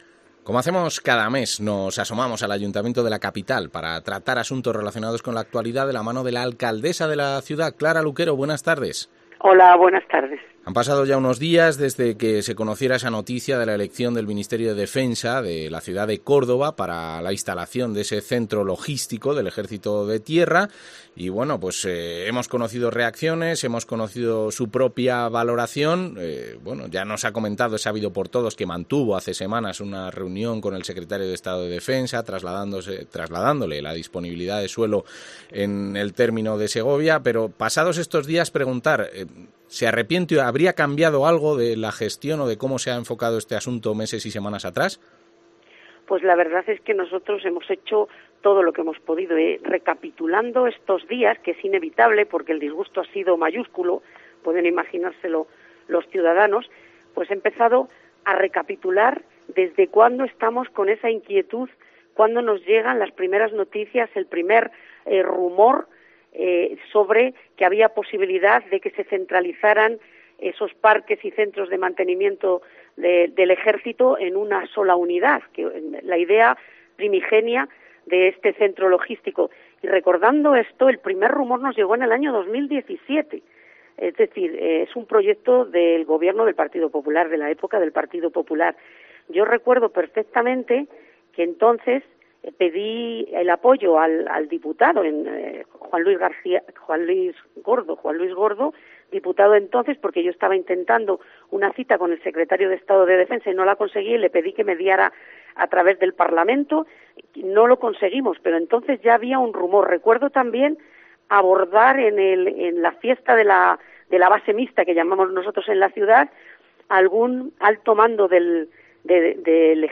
La alcaldesa de Segovia habla sobre la elección de Córdoba para el centro logístico del Ejército